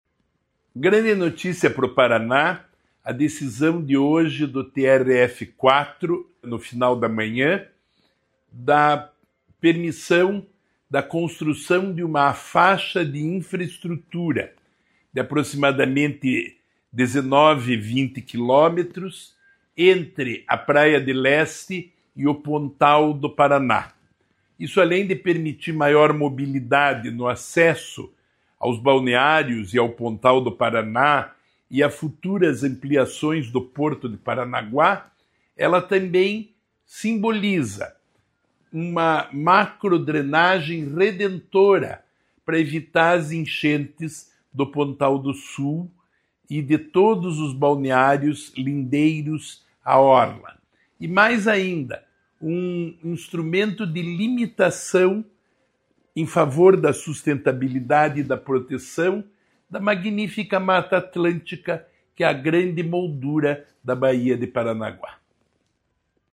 Sonora do secretário Estadual do Desenvolvimento Sustentável, Rafael Greca, sobre a decisão do TRF4 sobre a Faixa de Infraestrutura, no Litoral